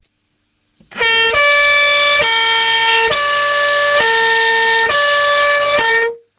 Fisa luchthoorn Impulsound 30 FPF2C 12V | 146195
Fisa luchthoorn Impulsound 30 FPF2C 12V Specificaties: - 118dB - 200W - 560Hz high tone - 460Hz low tone - 30 cycli per minuut